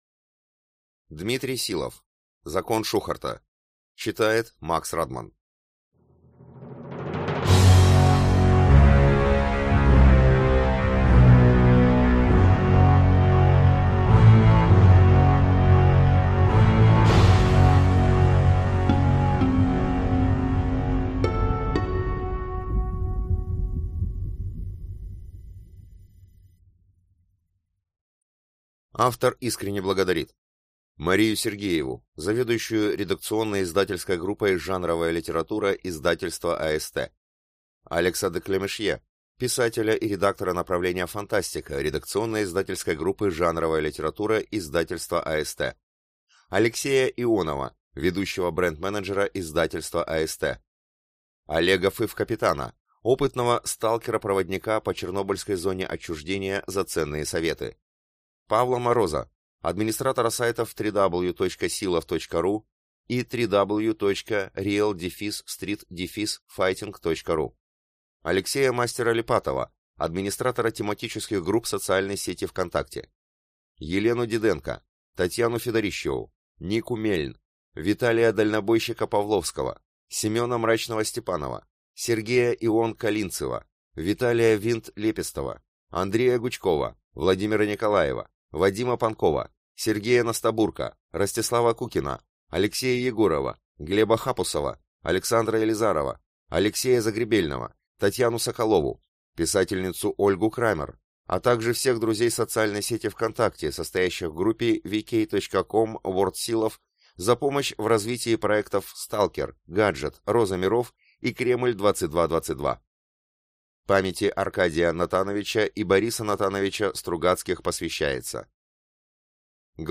Аудиокнига Закон Шухарта | Библиотека аудиокниг